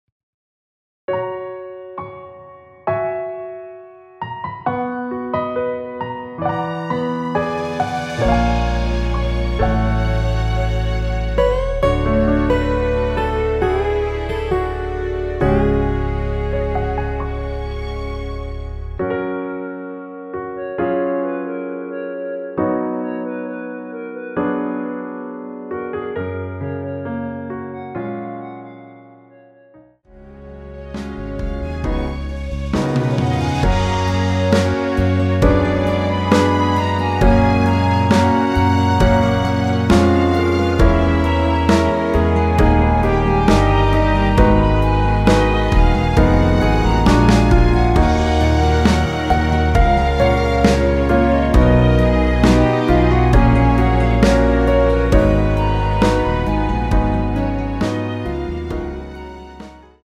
원키에서(-1)내린 멜로디 포함된 MR입니다.(미리듣기 확인)
Gb
앞부분30초, 뒷부분30초씩 편집해서 올려 드리고 있습니다.
중간에 음이 끈어지고 다시 나오는 이유는